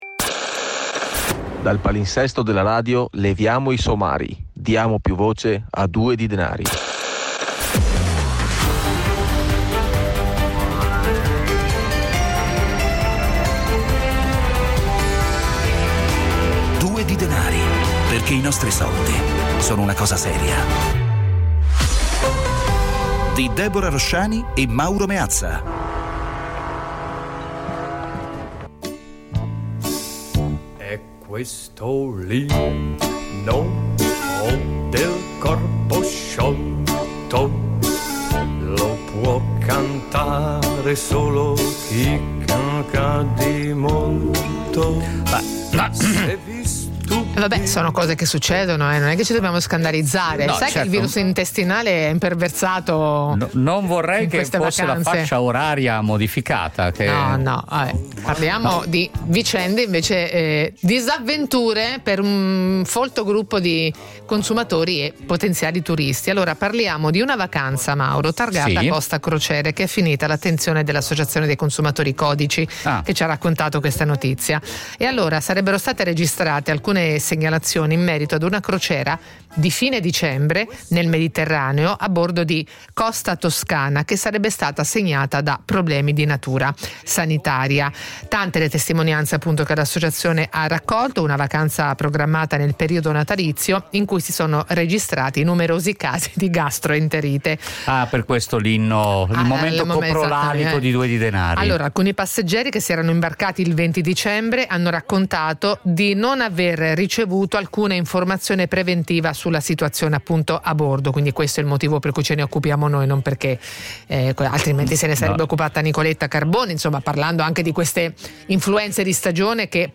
1 140. Il Consenso Informato nelle Terapie Psichedeliche (Live a Genova) 47:16